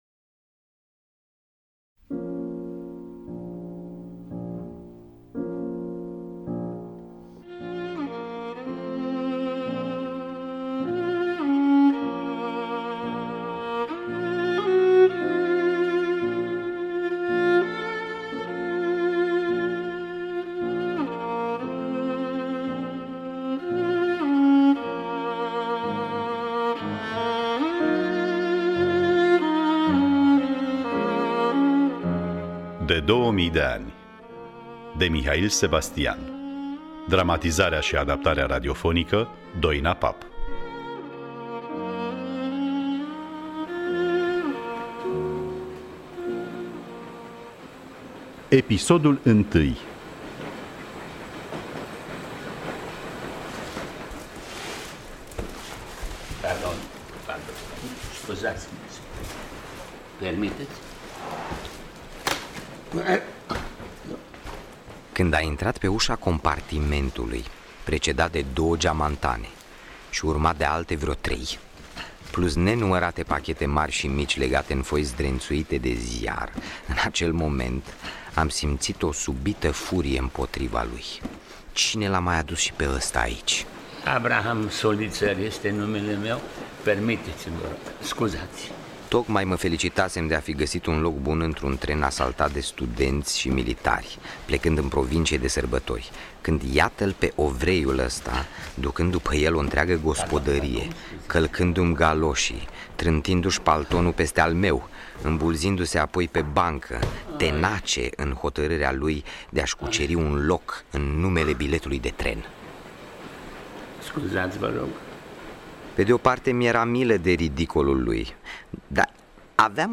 Dramatizarea şi adaptarea radiofonică